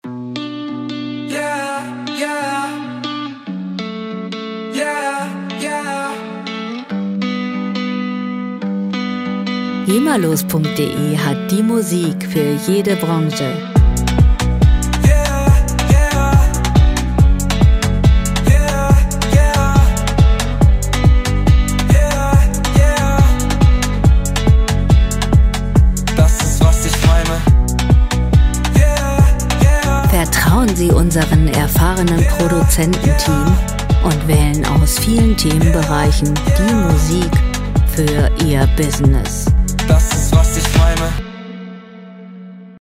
Musikstil: Indie-Pop
Tempo: 140 bpm
Tonart: H-Moll
Charakter: anklagend, betrüblich
Instrumentierung: Sänger, Synthesizer, E-Bass, E-Gitarre